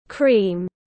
Cream /kriːm/